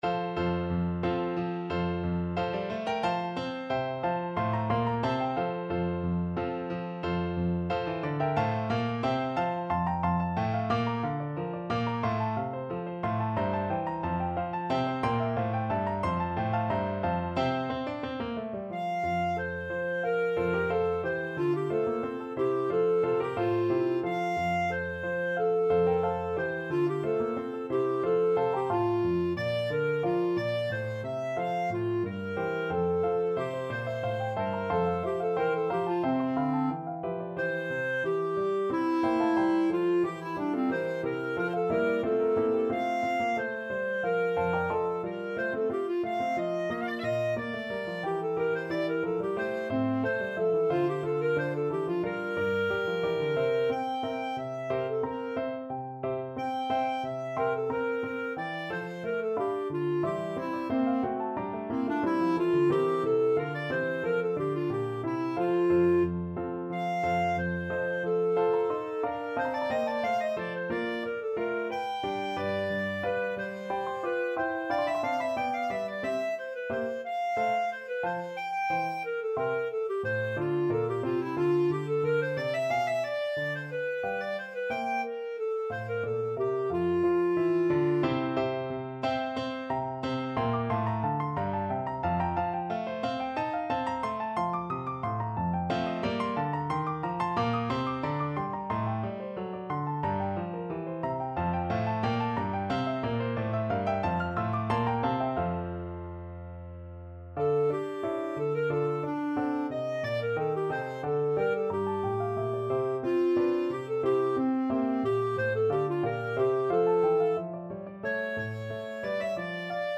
Classical
Clarinet version
Piano Playalong MP3